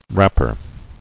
[rap·per]